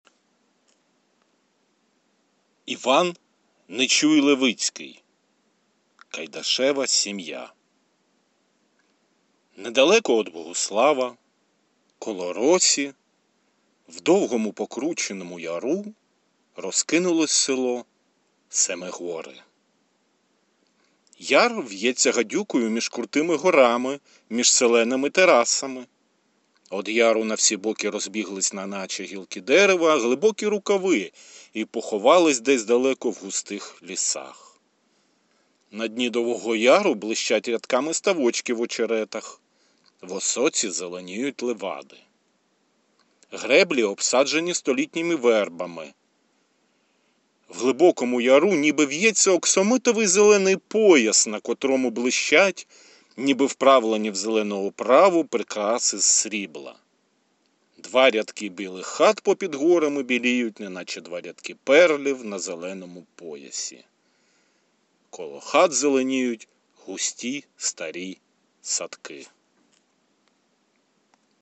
Кайдашева сім'я (уривок, аудіо)